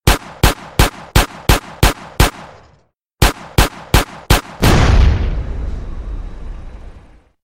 Play Pistol 7 5 Explosion San Andreas - SoundBoardGuy
pistol-7-5-explosion-san-andreas.mp3